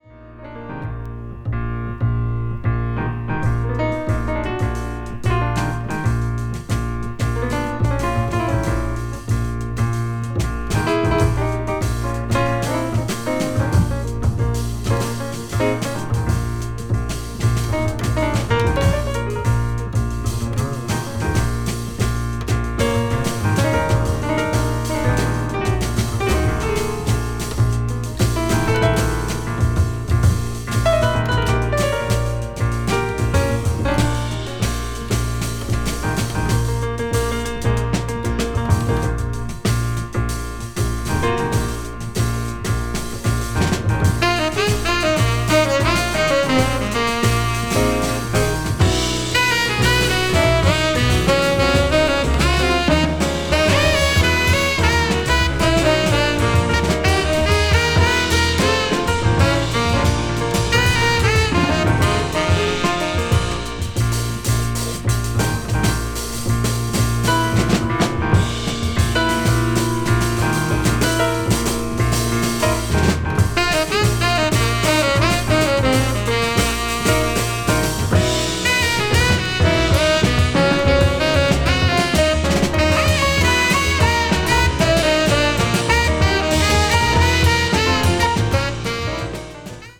contemporary jazz   deep jazz   modal jazz   spritual jazz